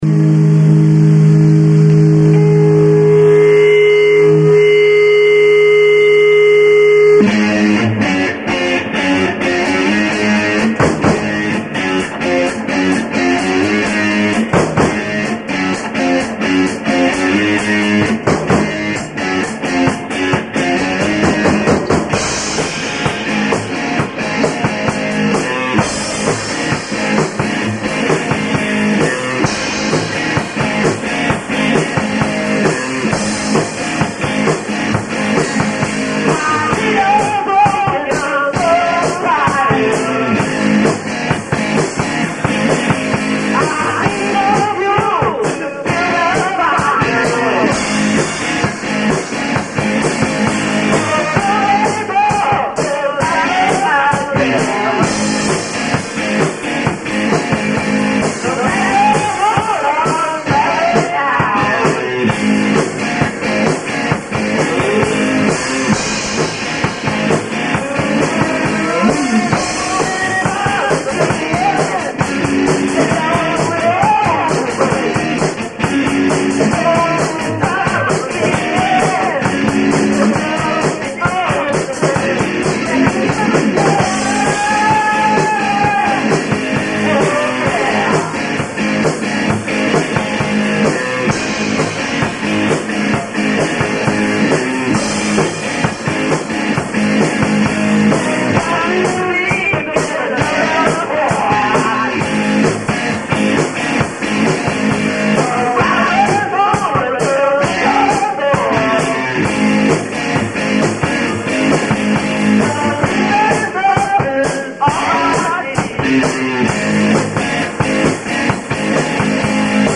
They are completely and unapologetically live.